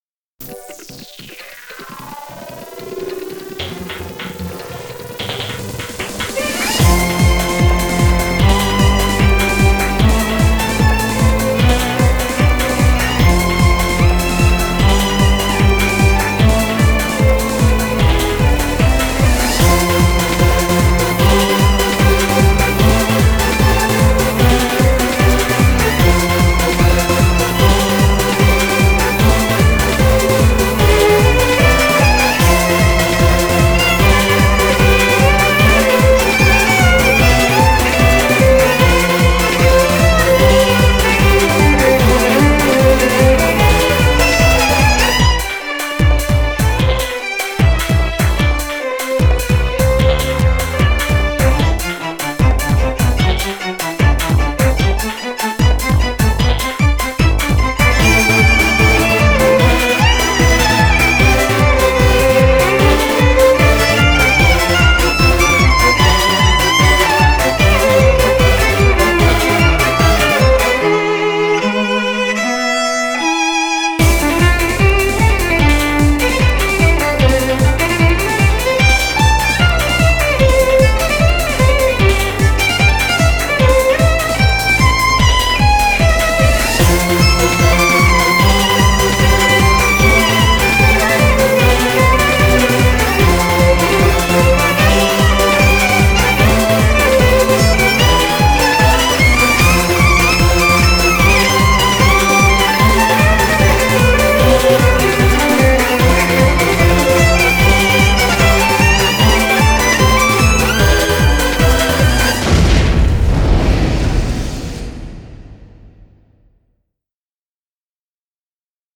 BPM150
Audio QualityPerfect (High Quality)
Genre: DANCE.